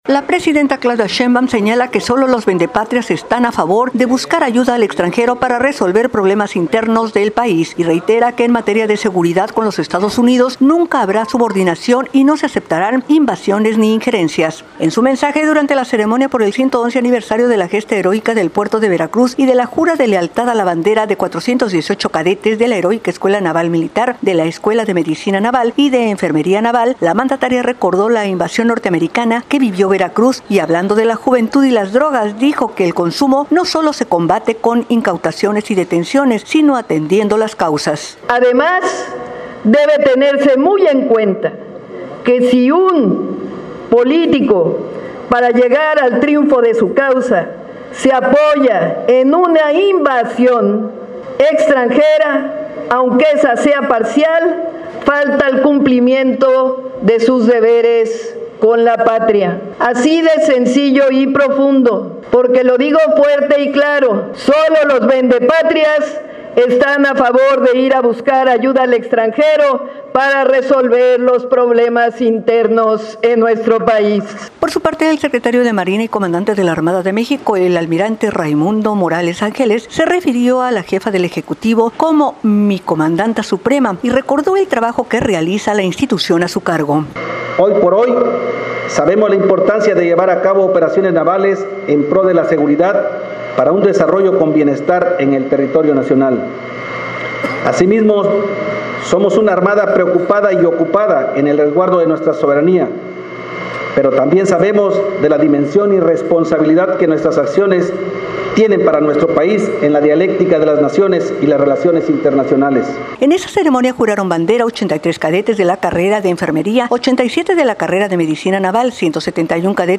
En su mensaje en la ceremonia por el 111 Aniversario de la Gesta Heroica del Puerto de Veracruz y de la jura de Lealtad a la Bandera de 418 cadetes de la Heroica Escuela Naval Militar, de la Escuela de Medicina Naval y de Enfermería Naval, la mandataria recordó la invasión norteamericana que vivió Veracruz e indicó que, el consumo de drogas no se combate con incautaciones y detenciones sino atendiendo las causas.